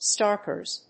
音節stark・ers 発音記号・読み方
/stάɚkɚz(米国英語), stάːkəz(英国英語)/